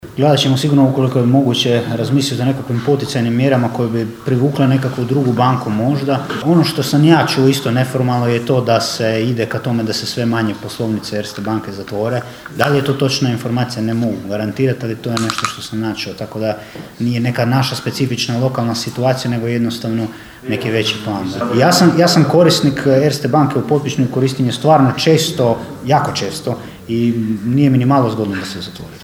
Na sjednici Općinskog vijeća Kršana raspravljalo se o najavi zatvaranja poslovnice Erste banke u Potpićnu.
ton – Ana Vuksan), dok je predsjednik Vijeća Ivan Zambon dodao: (